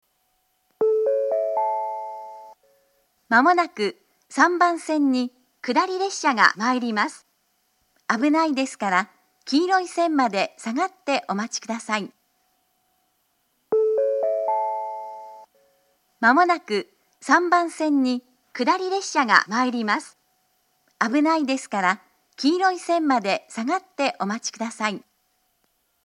接近放送前のチャイムは上下で何故か異なるものを使用しています。
３番線下り接近放送